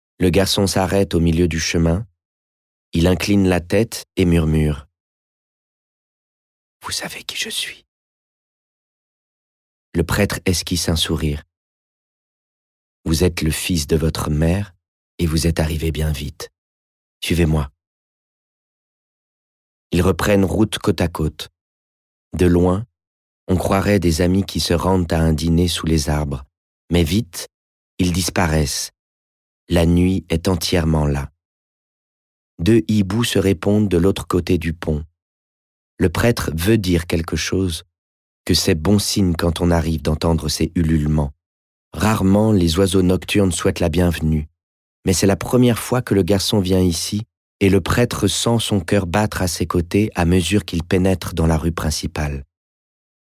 Livres Audio